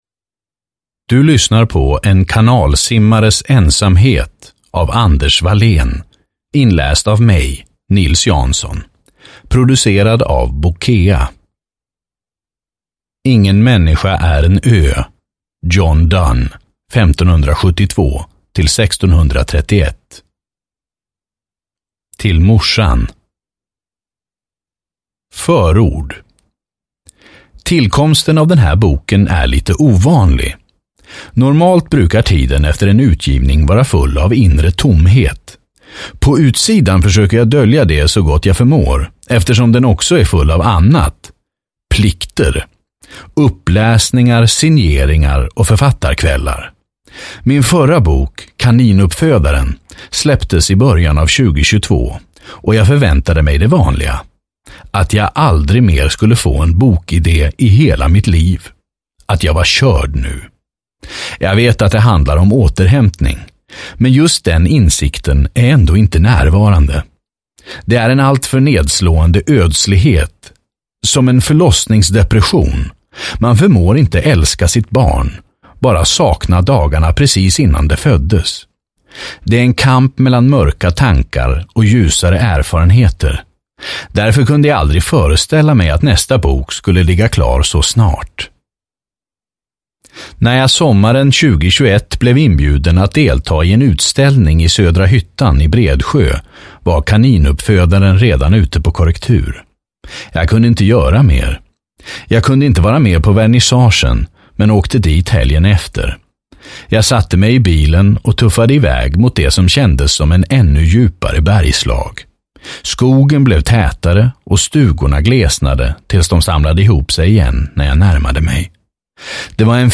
En kanalsimmares ensamhet (ljudbok) av Anders Wallén | Bokon